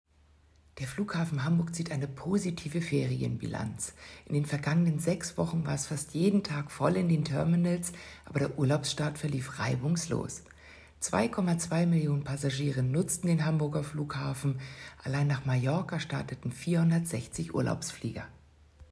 Pressemitteilung